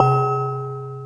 SMALL CHURCH BELL